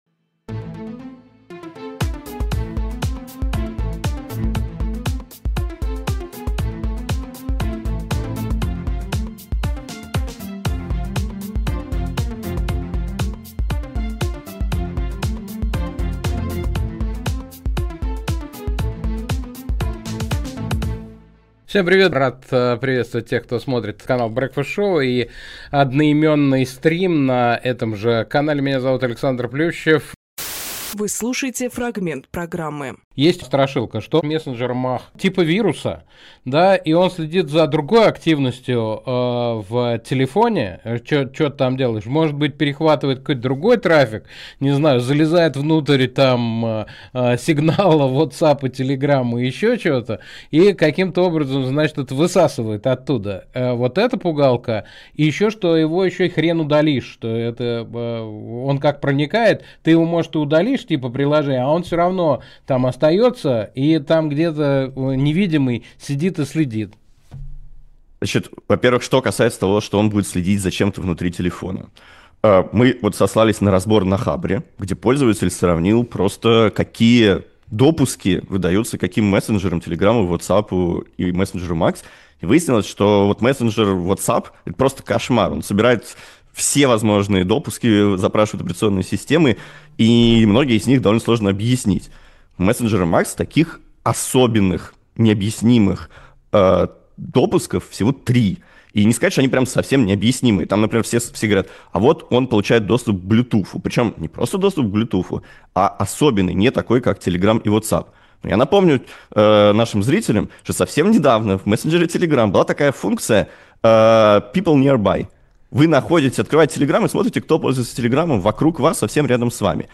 Фрагмент эфира от 26.08.25